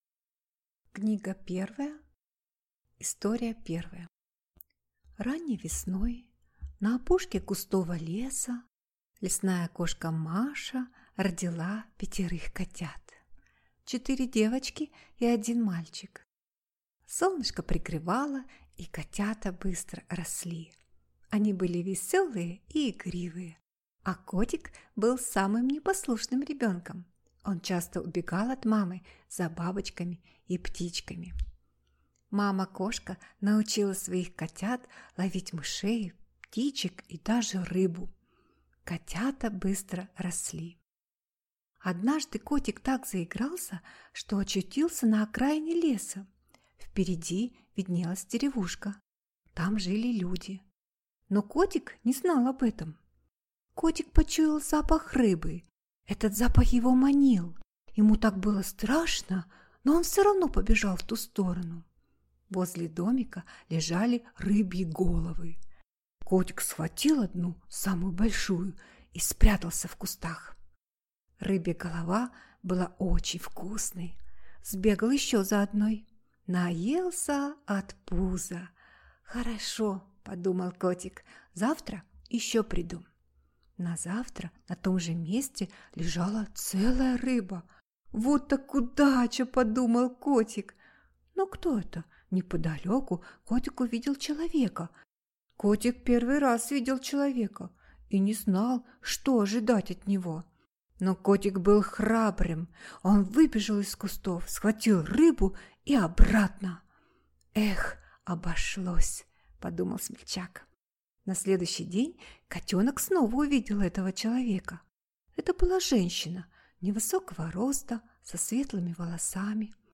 Аудиокнига Сказка о приключениях лесного кота Барсика | Библиотека аудиокниг